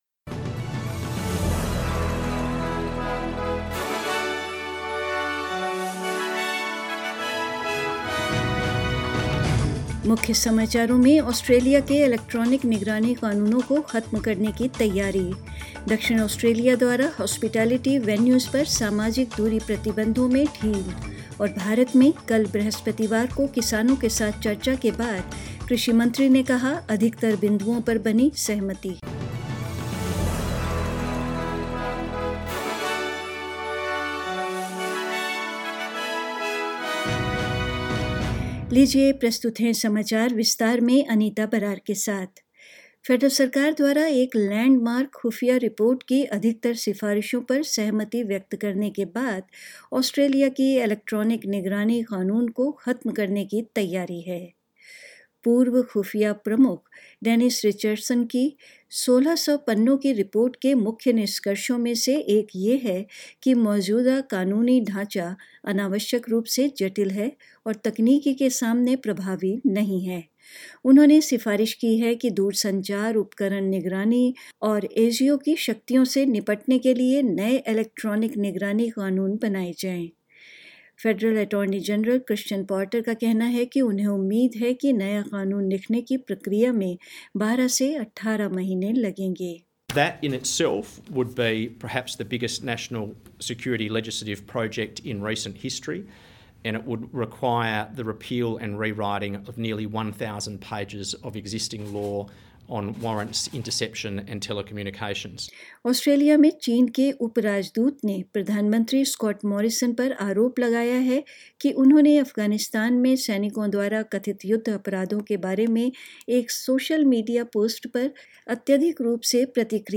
News in Hindi 4th December 2020